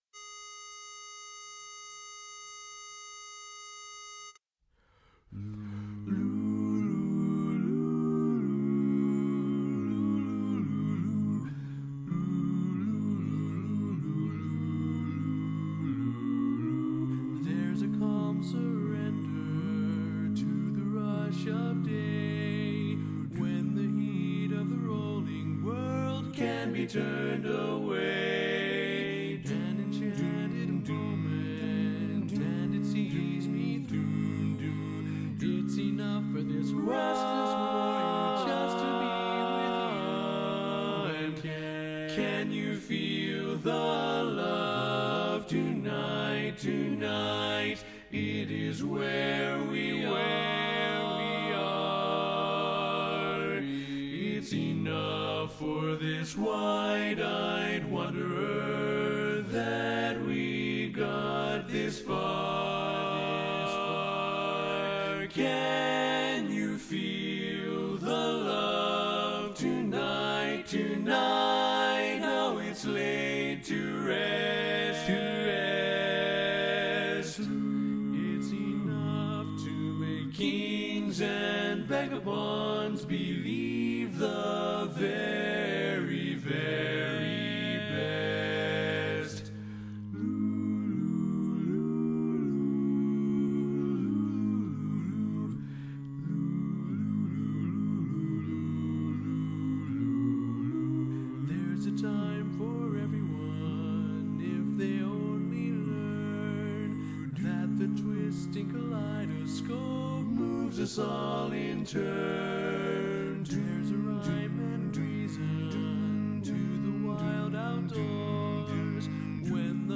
Kanawha Kordsmen (chorus)
Up-tempo
A♭Major